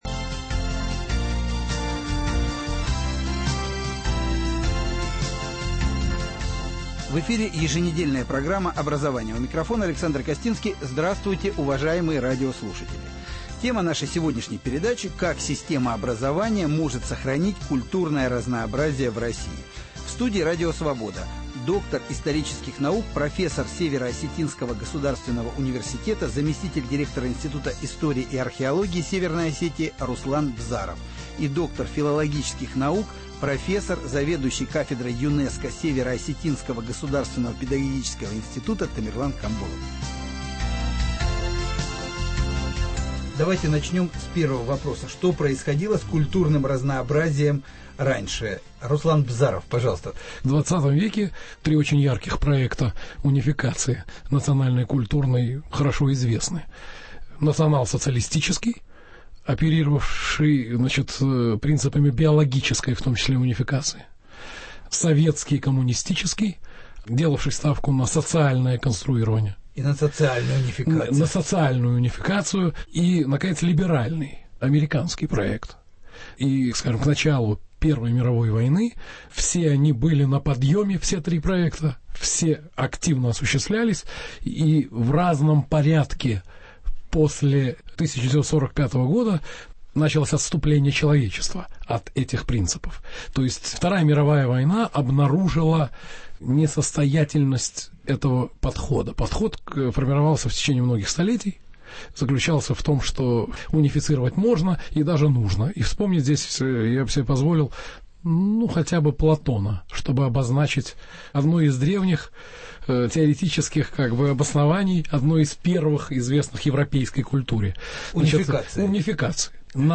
Как система образования может сохранить культурное разнообразие в России. В студии Радио Свобода